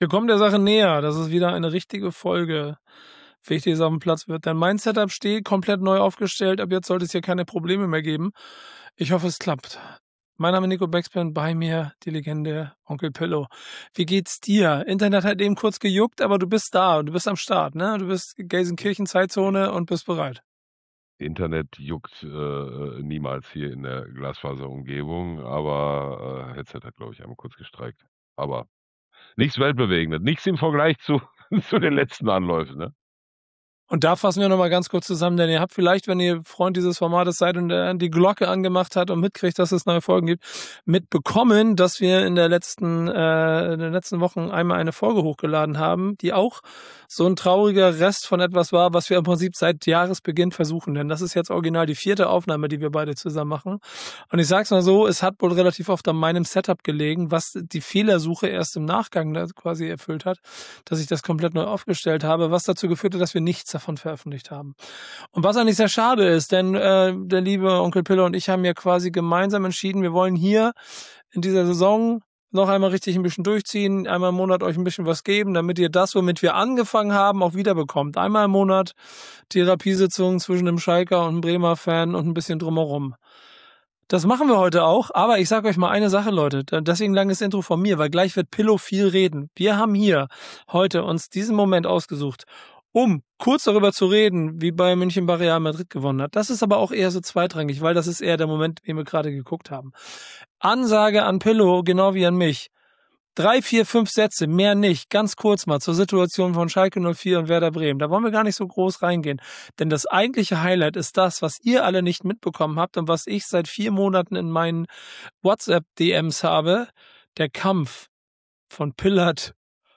Vierte Aufnahme, neues Setup, endlich wieder Therapiesitzung.